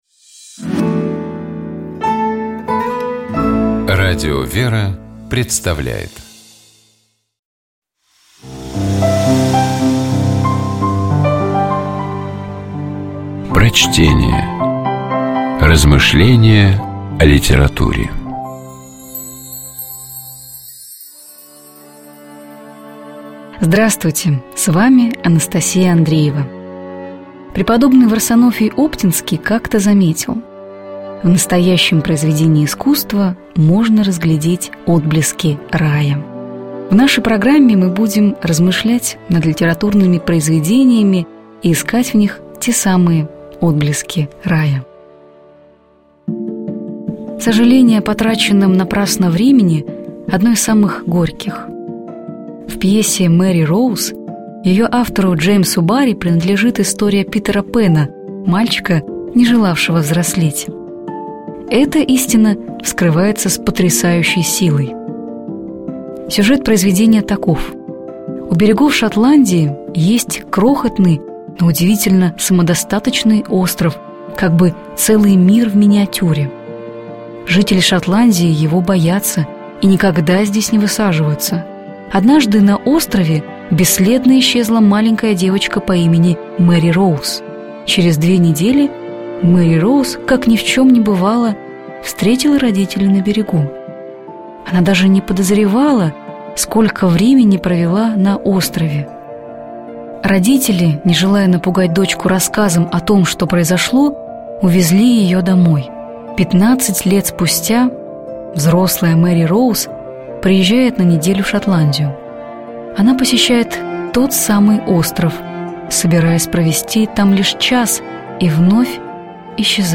Prochtenie-Dzh_-Barri-pesa-Mjeri-Rouz-Greh-kradet-zhizn.mp3